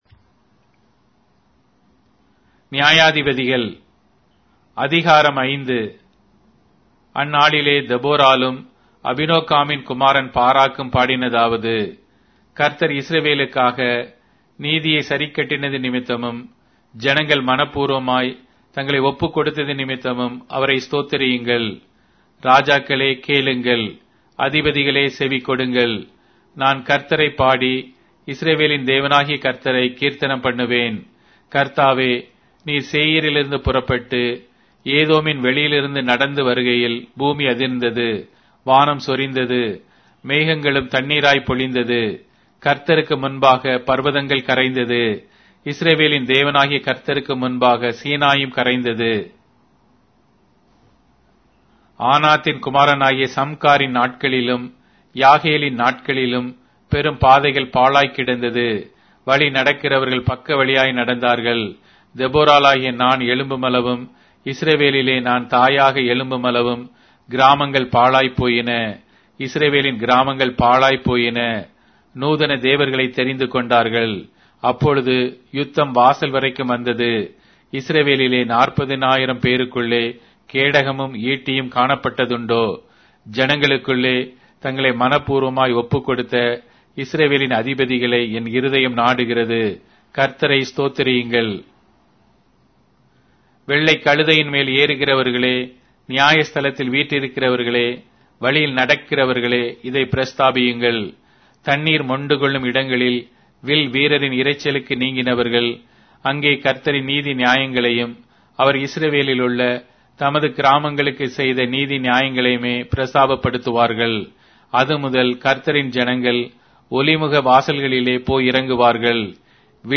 Tamil Audio Bible - Judges 21 in Litv bible version